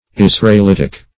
Search Result for " israelitic" : The Collaborative International Dictionary of English v.0.48: Israelitic \Is`ra*el*it"ic\, Israelitish \Is"ra*el*i`tish\, a. Of or pertaining to Israel, or to the Israelites; Jewish; Hebrew.